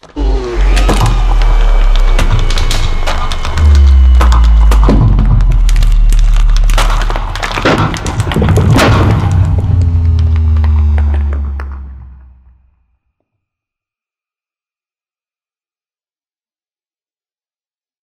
Sound / Minecraft / mob / enderdragon / end.ogg